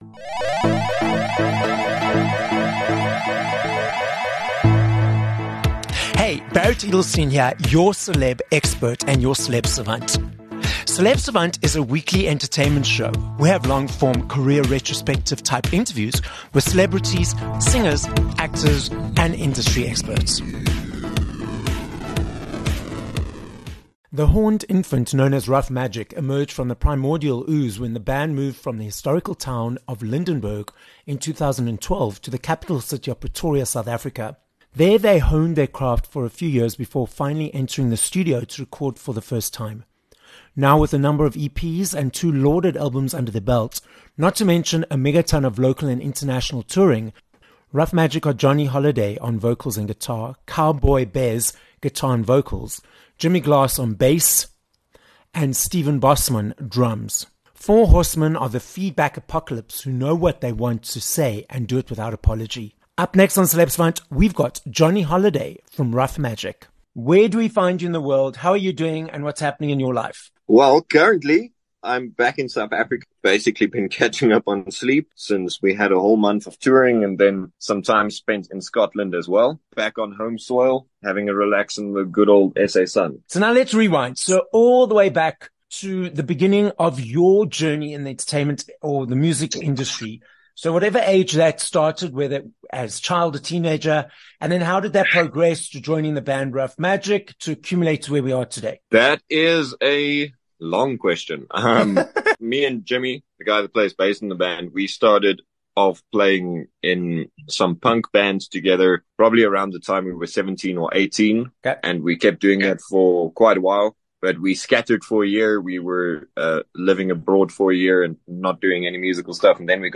5 Oct Interview